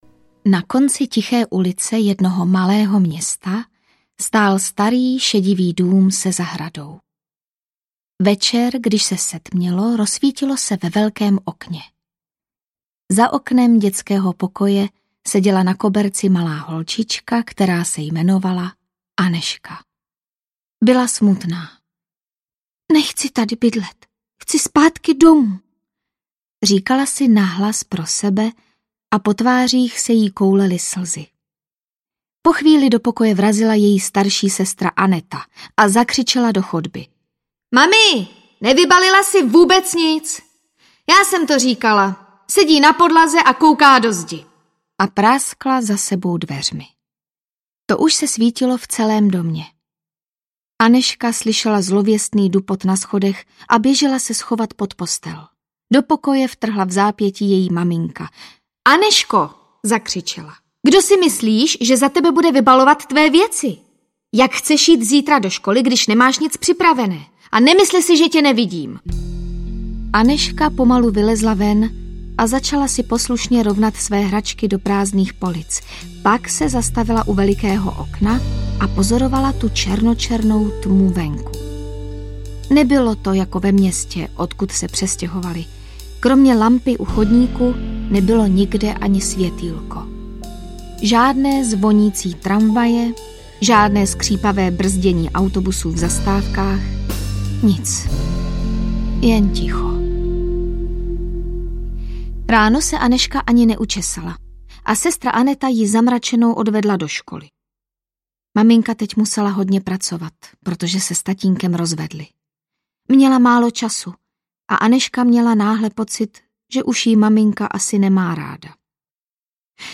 Kouzlení vše nezmění audiokniha
Ukázka z knihy